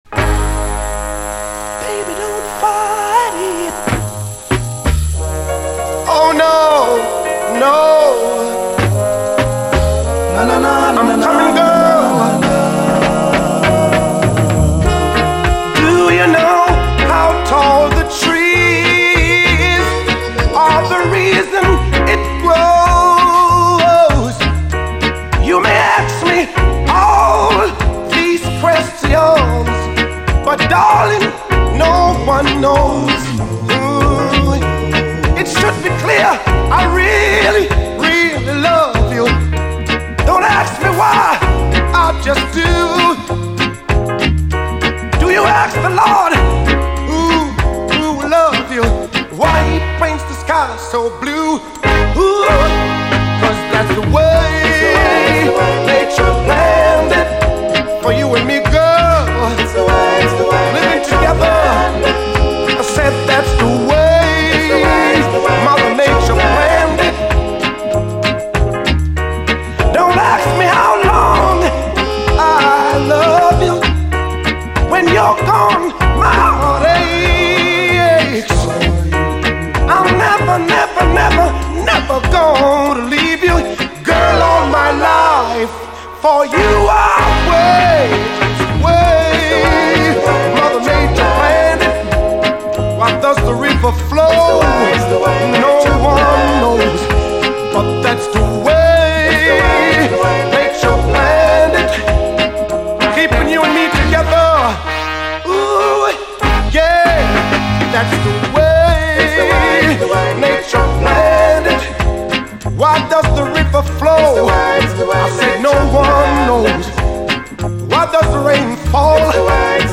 REGGAE, 7INCH
最高ジャマイカン・ソウル！